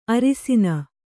♪ arisina